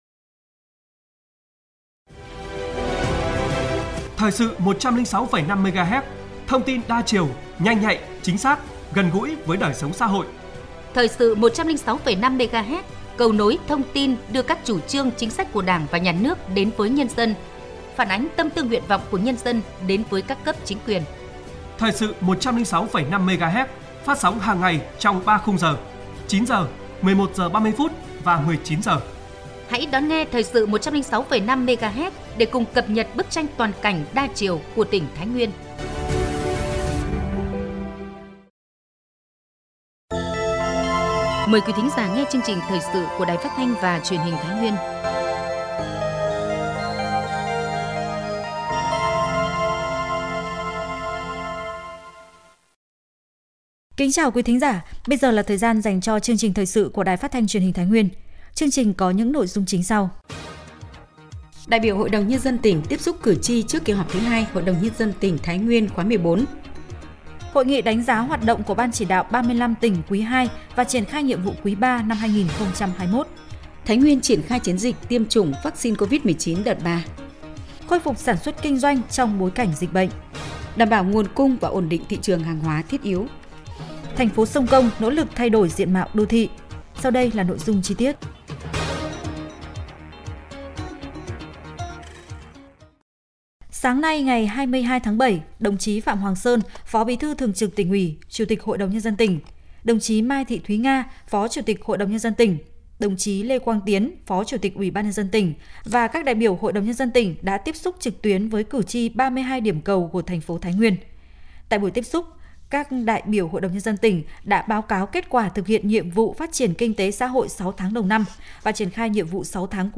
Thời sự tổng hợp Thái Nguyên ngày 25/7/2021